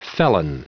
Prononciation du mot felon en anglais (fichier audio)
Prononciation du mot : felon